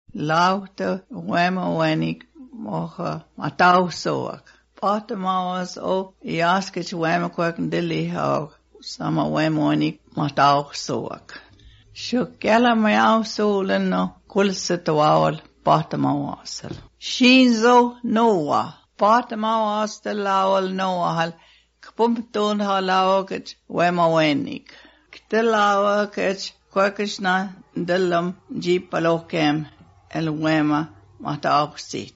24 February 2020 at 1:46 am Judging from the somewhat monotone aspect of the language as well as the timbre of the voice and the consonant sounds I think it is a North American Indian language.